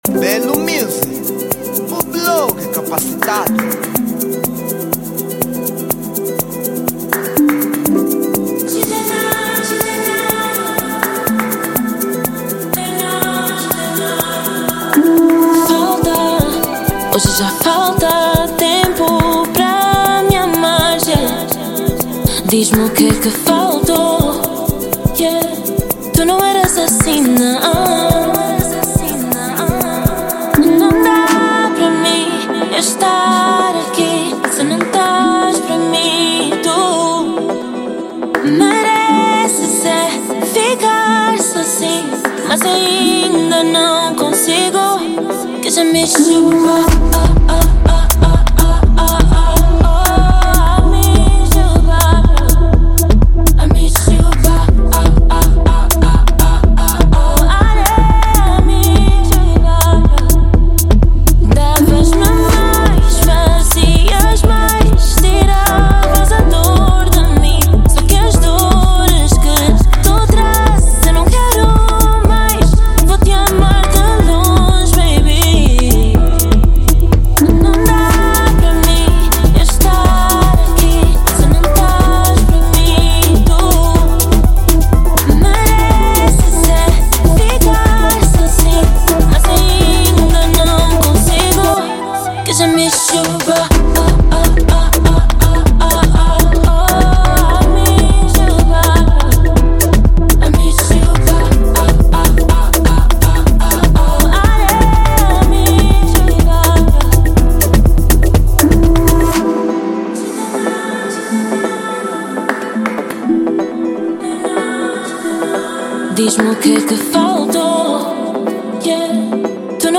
Género : Afro Dance